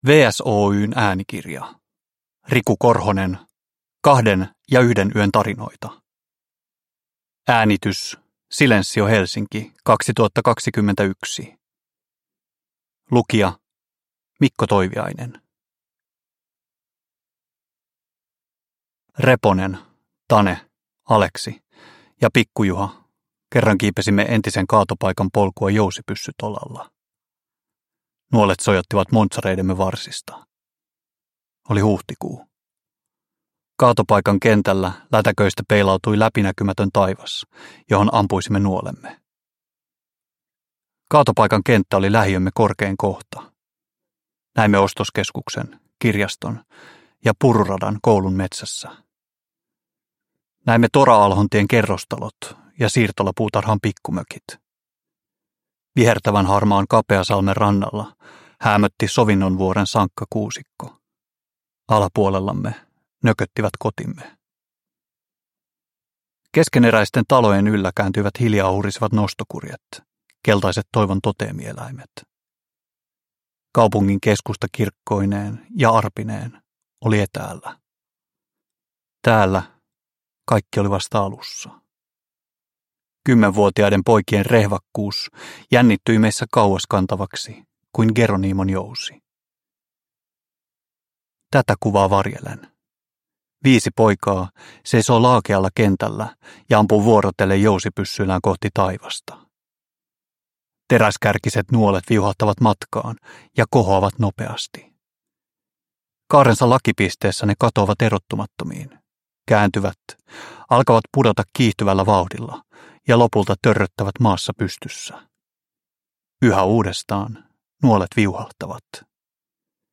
Kahden ja yhden yön tarinoita (ljudbok) av Riku Korhonen | Bokon